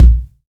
INSKICK13 -L.wav